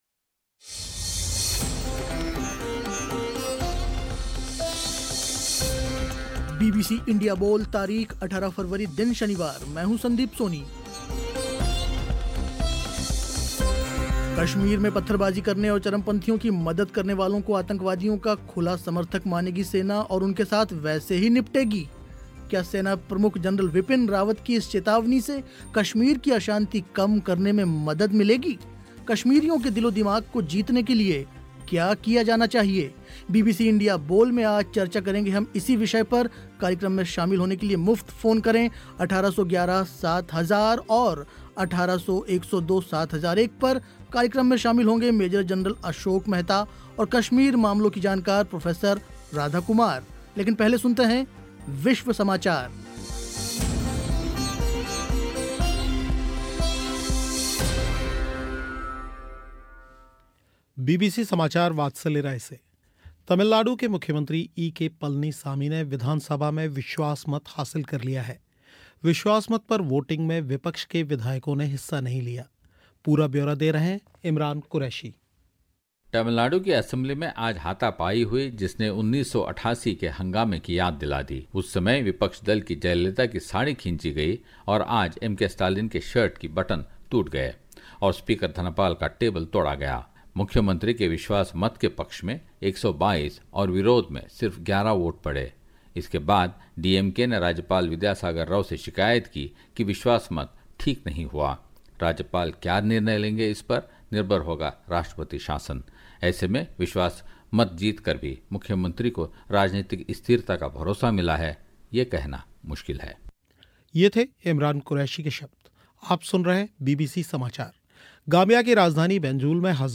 कश्मीरियों के दिलो-दिमाग को जीतने के लिए क्या किया जाना चाहिए. बीबीसी इंडिया बोल में आज चर्चा हुई इसी विषय पर.